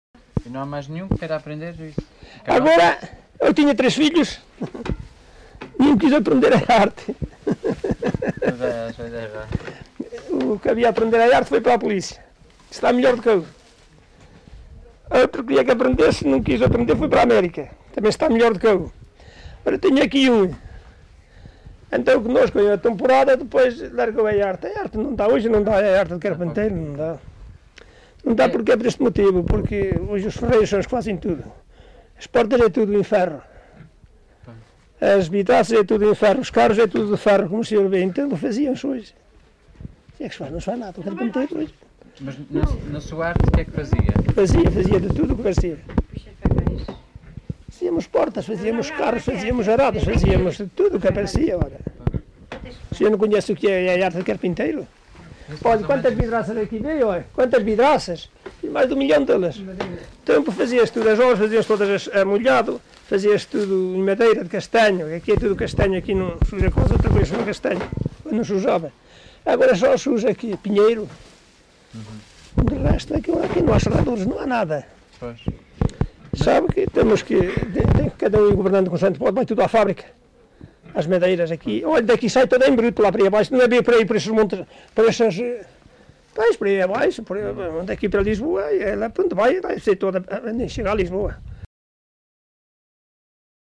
LocalidadeSanto André (Montalegre, Vila Real)